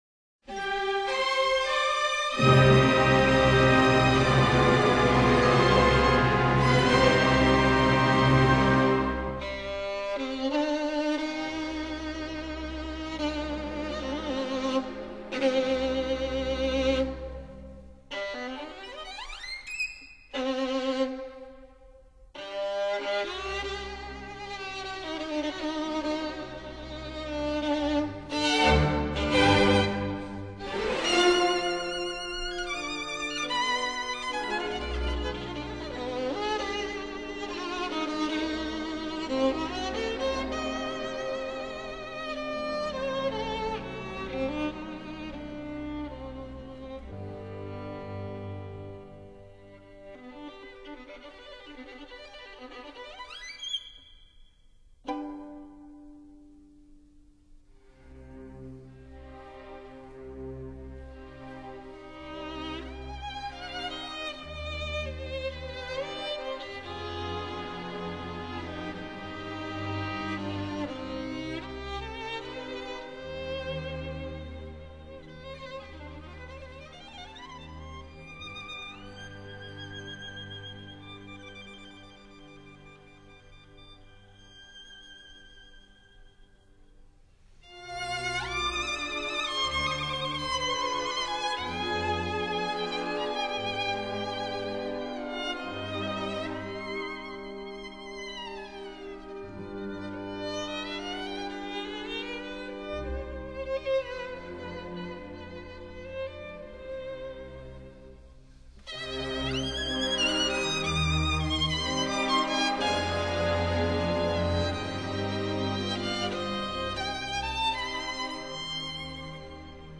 violon}